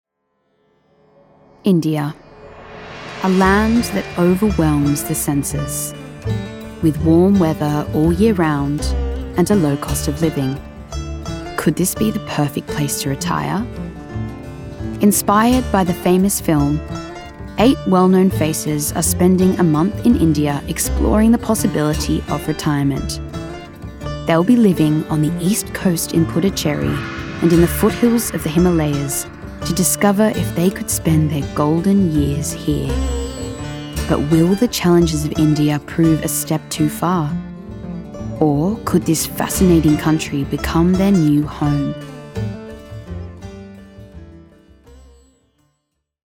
Female
English (Australian)
Documentary
Australian Documentary Example